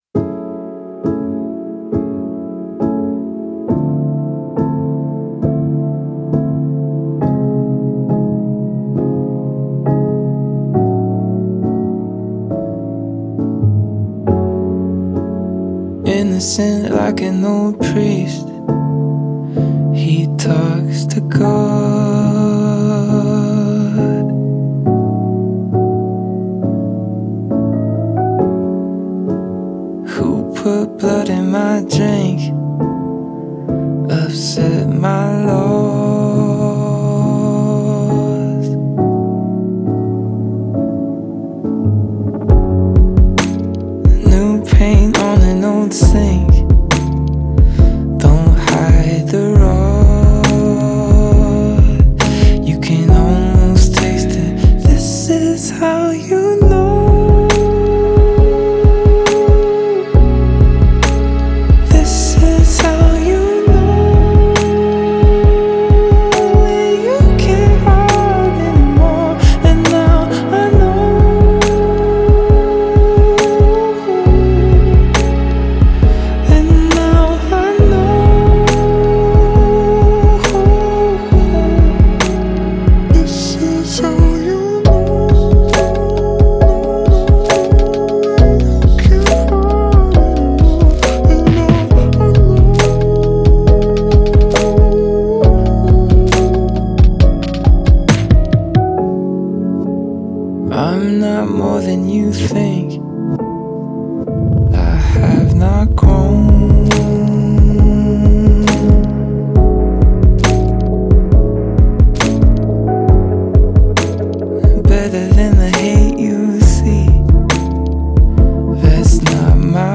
Alternative/Indie